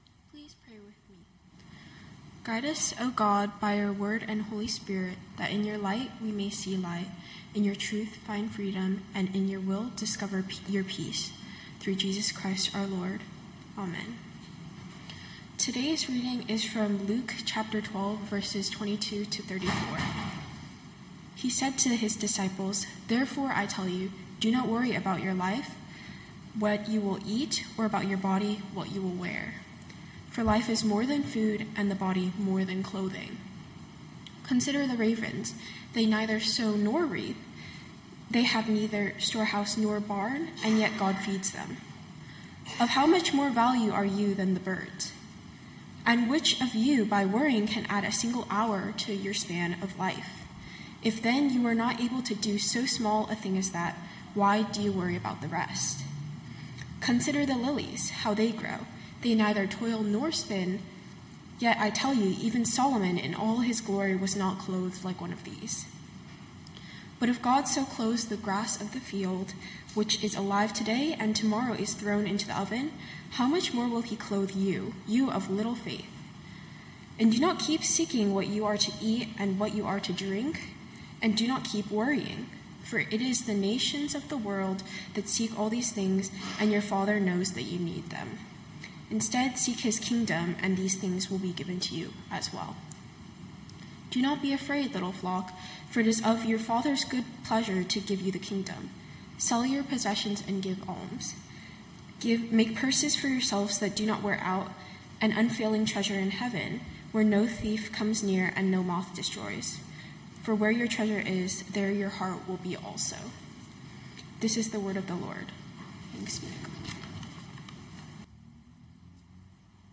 Knox Pasadena Sermons The Weight of Worry Sep 21 2025 | 00:22:00 1x 00:00 / 00:22:00 Subscribe Share Spotify RSS Feed Share Link Embed